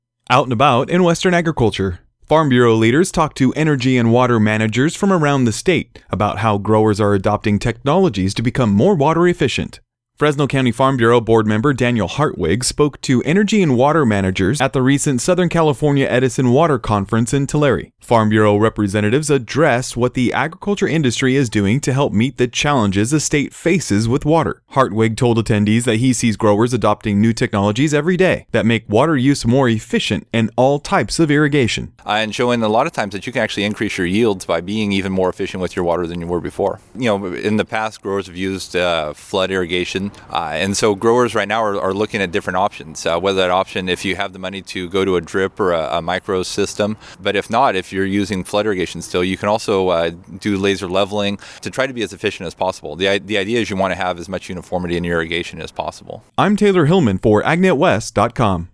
Farm Bureau leaders told energy and water managers from around the state how producers are adopting technology to become more water efficient.